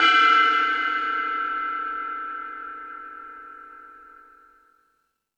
Metal Drums(40).wav